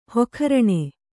♪ hokharaṇe